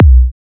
edm-kick-02.wav